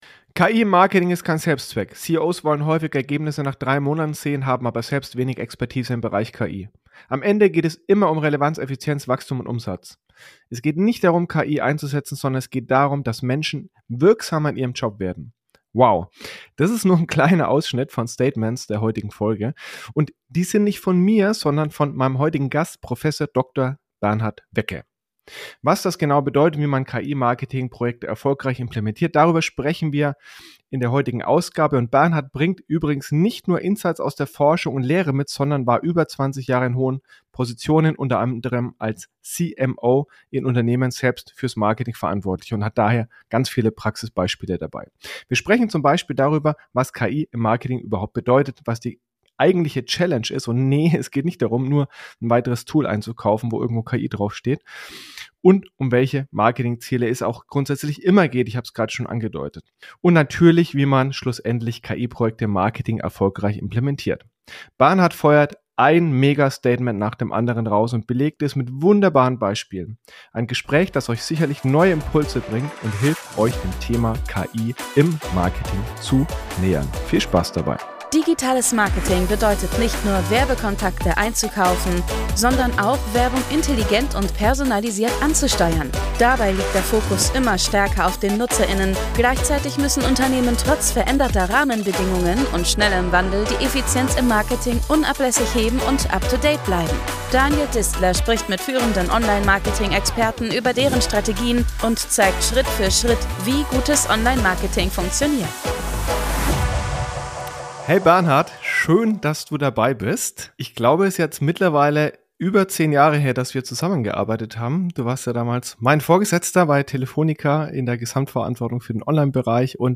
Ein Gespräch das euch ganz sicher neue Impulse bringt und euch helfen wird das Thema KI Marketing zielgerichteter anzugehen.